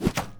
arrow-impact-3.mp3